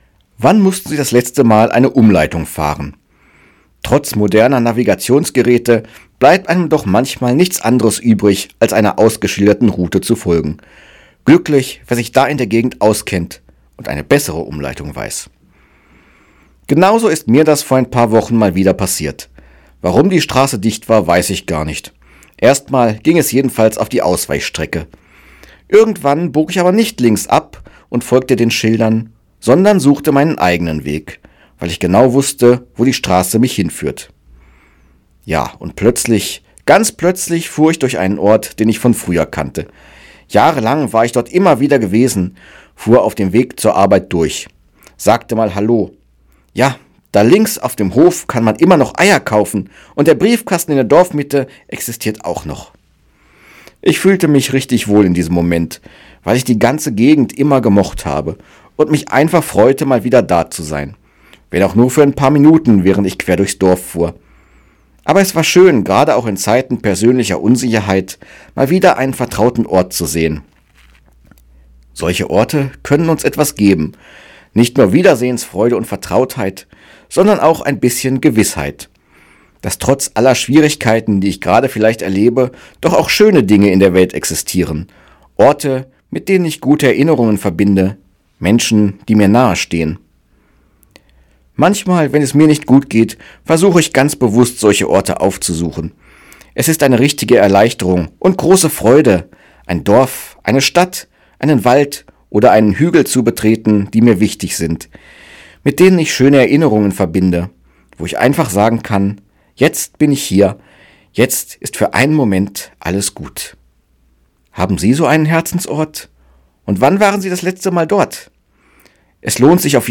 Radioandacht vom 13. November